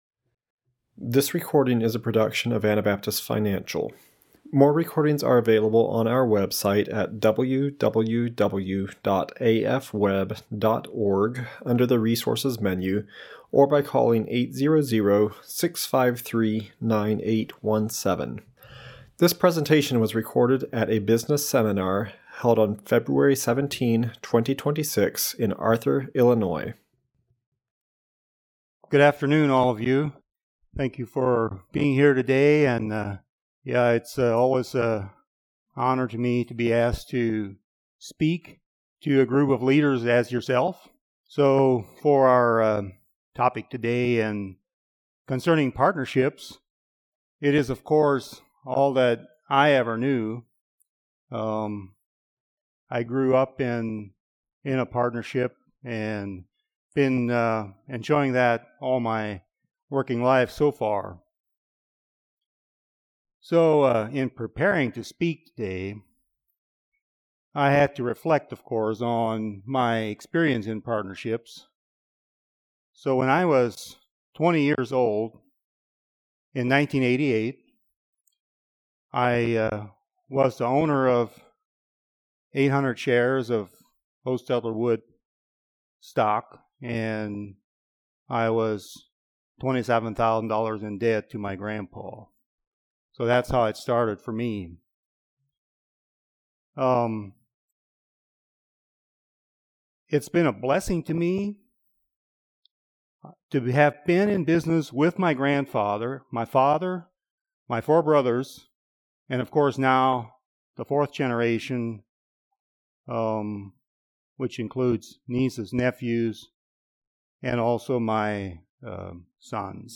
Illinois Business Seminar 2026